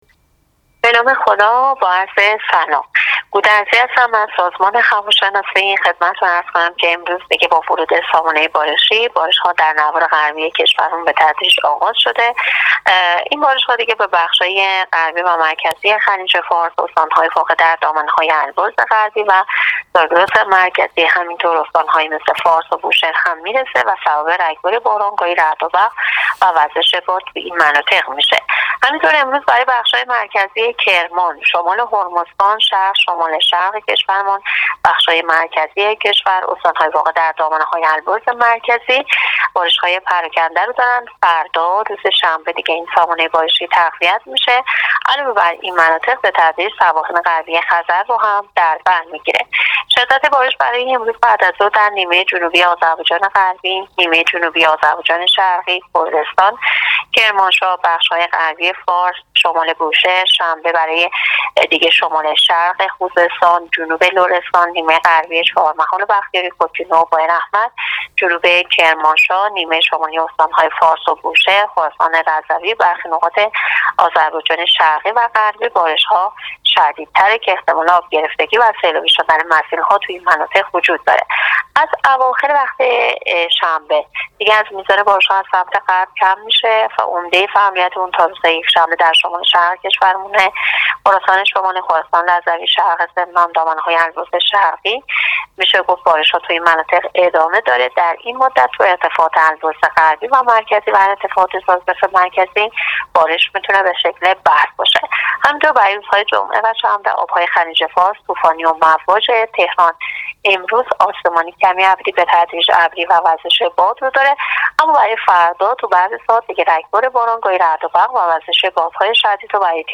رادیو اینترنتی پایگاه خبری وزارت راه و شهرسازی گزارش می‌دهد؛